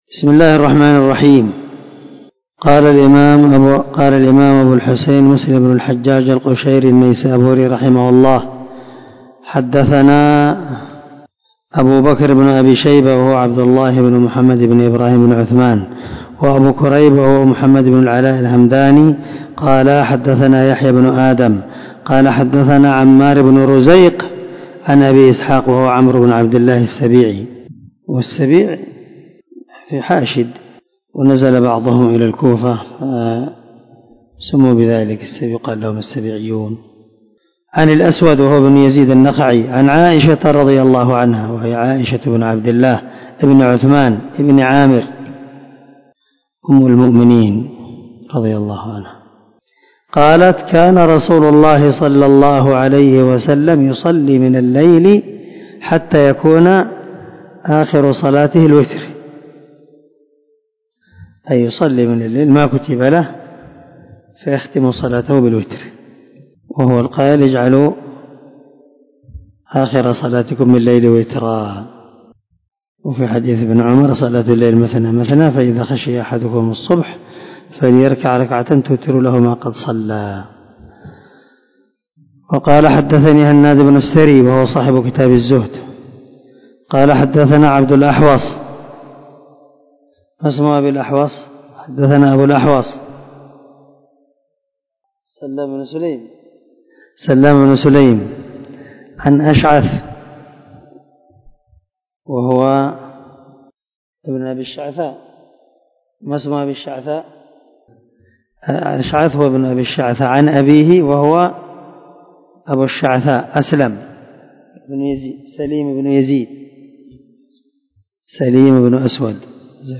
456الدرس 24 من شرح كتاب صلاة المسافر وقصرها حديث رقم ( 740 – 745 ) من صحيح مسلم
دار الحديث- المَحاوِلة- الصبيحة.